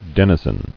[den·i·zen]